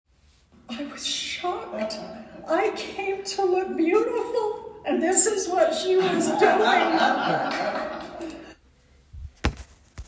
Storytelling